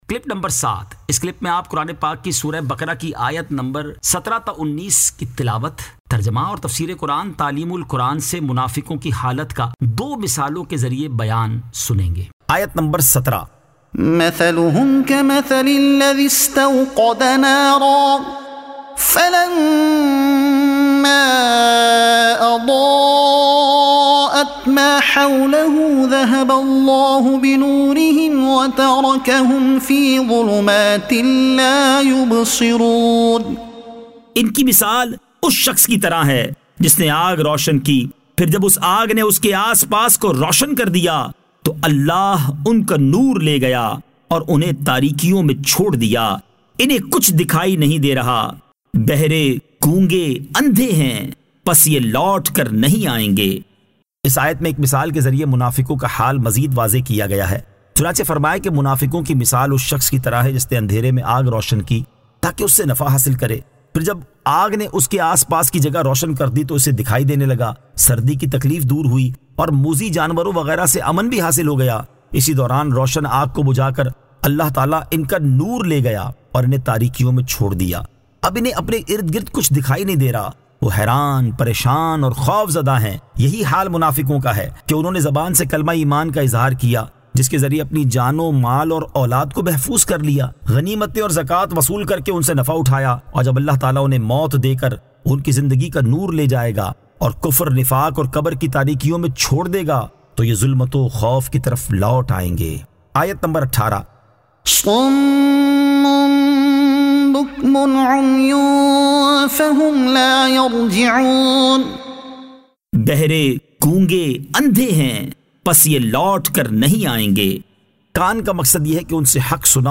Surah Al-Baqara Ayat 17 To 19 Tilawat , Tarjuma , Tafseer e Taleem ul Quran